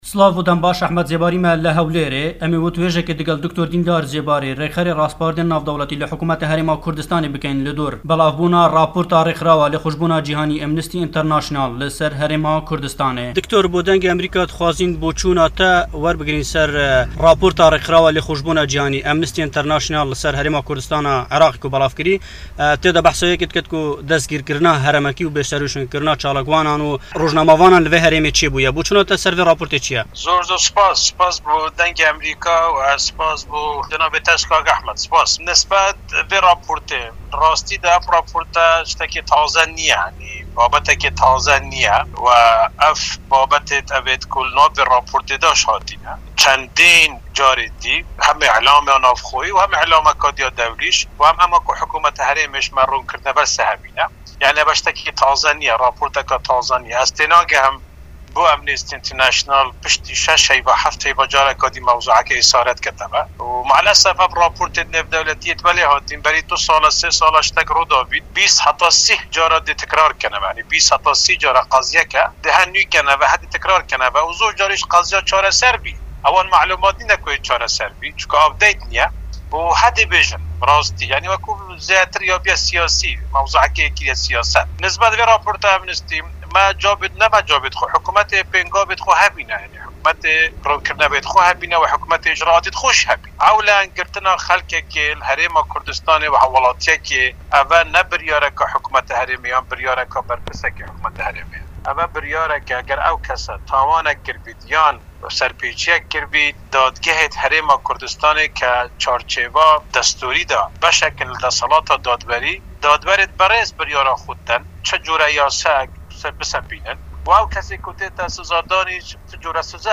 Rêxerê Raspardên Navneteweyî yê Hikumeta Herêma Kurdistanê Dr. Dîndar Zêbarî derbarê raporta Amnesty International ya li ser binpêkirinên mafan li Herêma Kurdistanê bersiva pirsên Derngê Amerîka dide.
Raporta Hewler Hevpeyvîn li Gl Dîndar Zêbarî